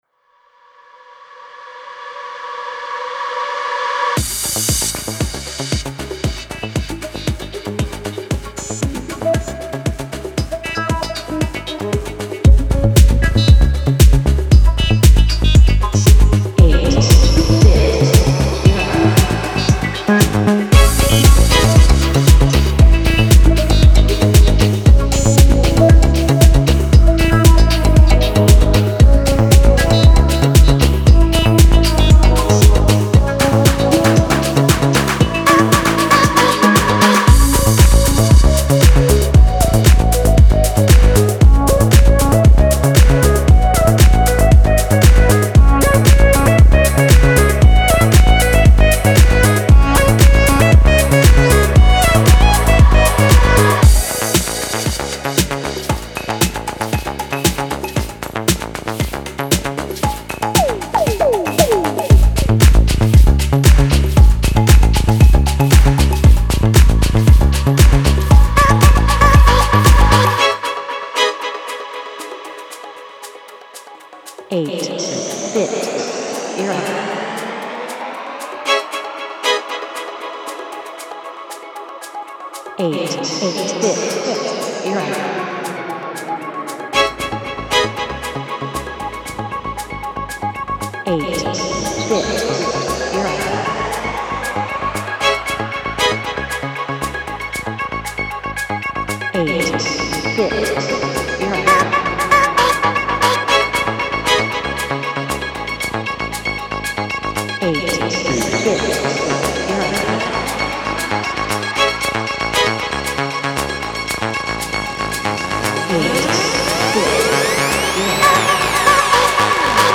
Genre : Deep House